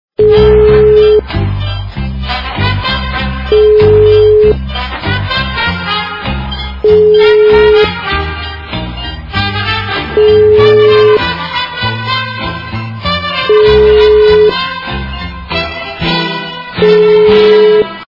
» Реалтоны » фильмы и телепередач » Инструментальная версия